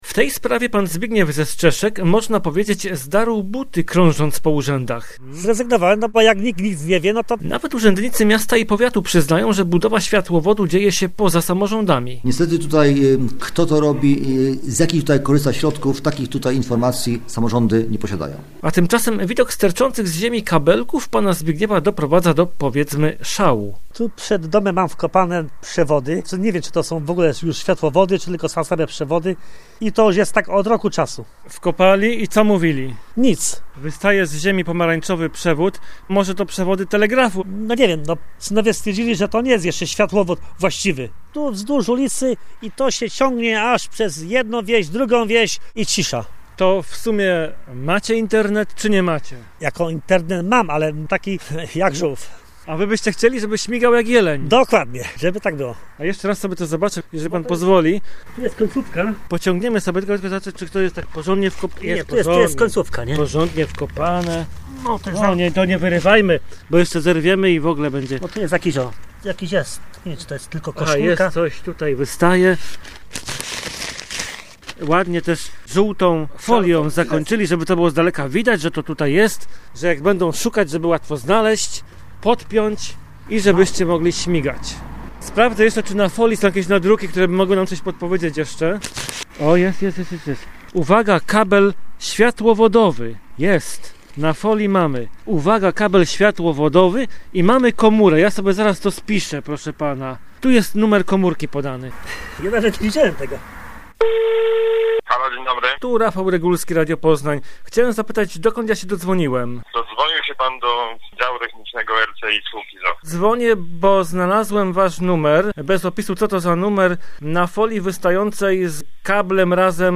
Relacjonuje